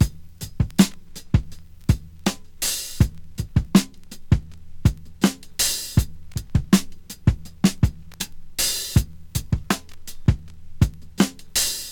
• 81 Bpm HQ Drum Beat E Key.wav
Free drum loop sample - kick tuned to the E note. Loudest frequency: 2265Hz
81-bpm-hq-drum-beat-e-key-nHF.wav